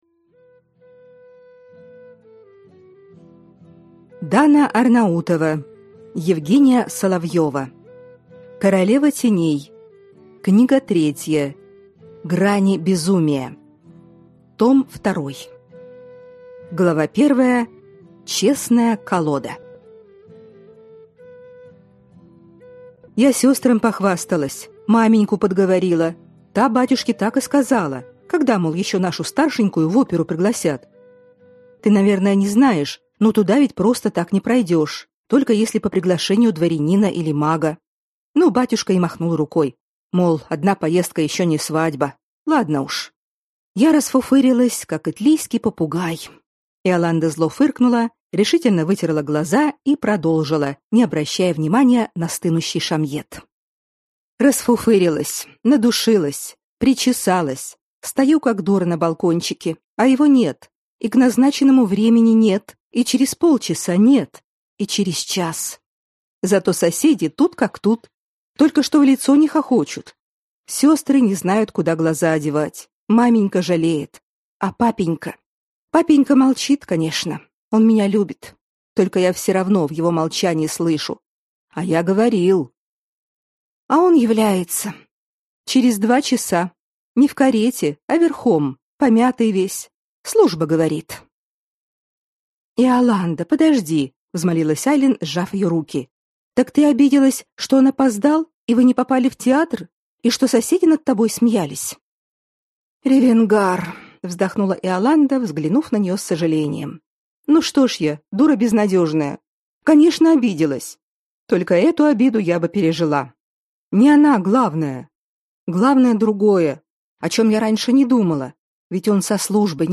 Аудиокнига Грани безумия. Том 2 | Библиотека аудиокниг